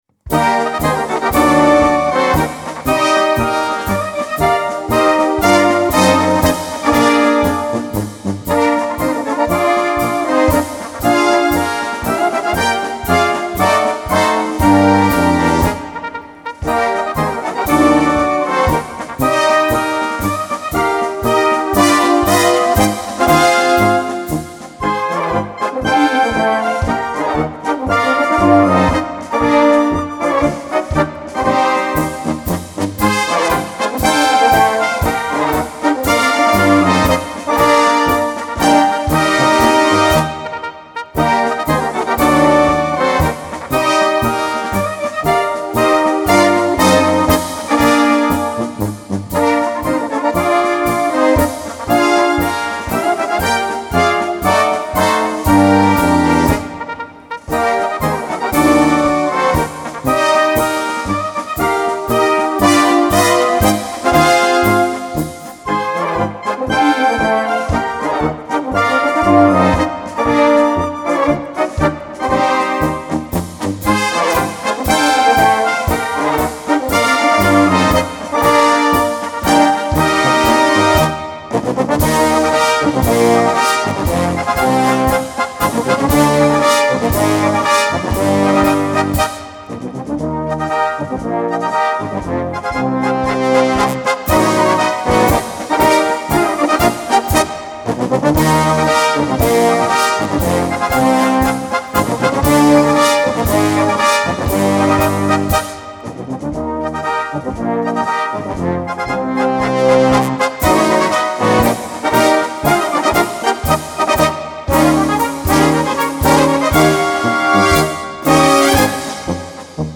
Marsch